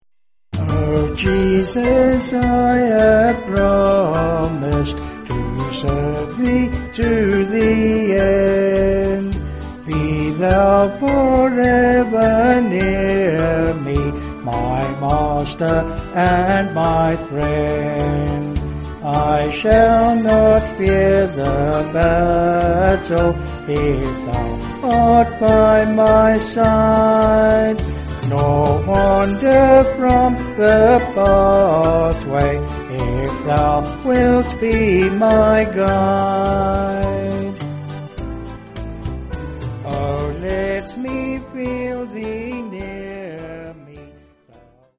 Vocals and Band
263.9kb Sung Lyrics